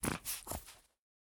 sounds / mob / fox / idle6.ogg